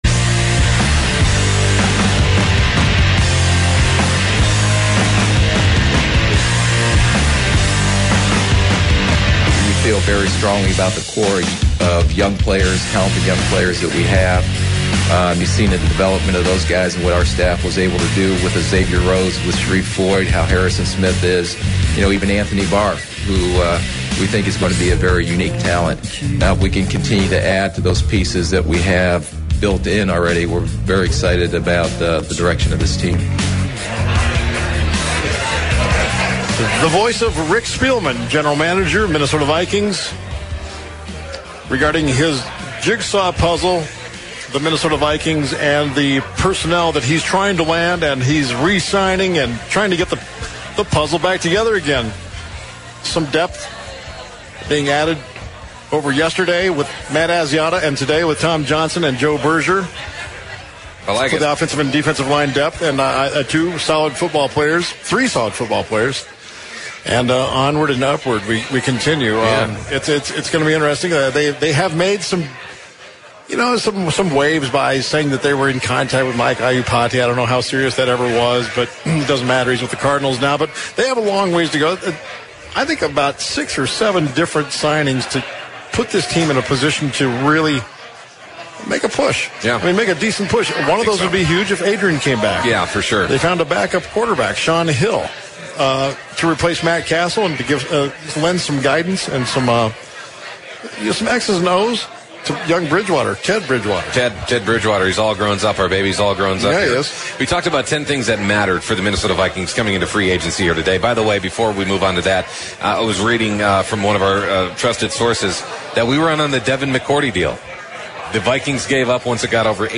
Hour two of Radioactive Sports from Tom Reid's Pub kicks off with more on NFL Free Agency.